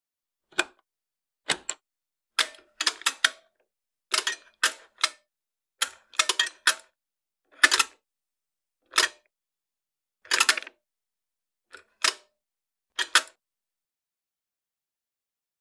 household
Dryer Switches and Dials